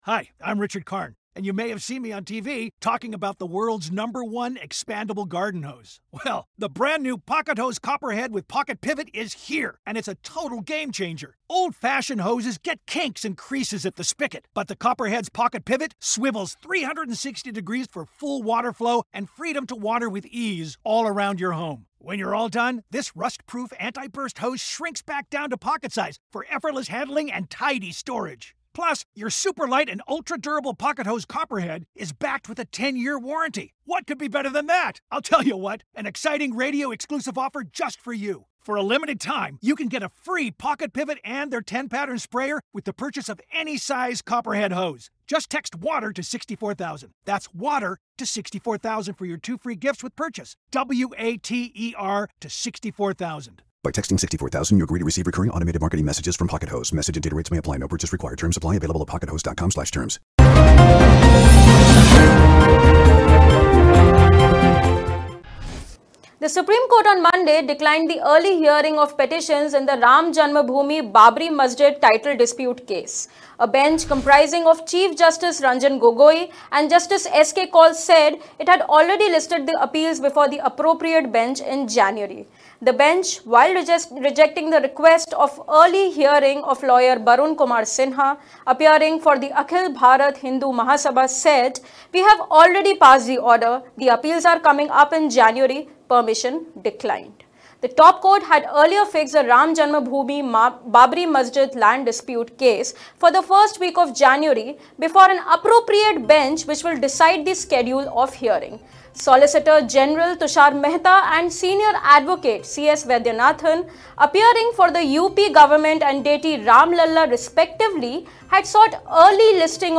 News Report / "Permission declined" says the SC on hearing of pleas in Ayodhya-Babri Masjid title dispute case